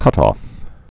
(kŭtôf, -ŏf)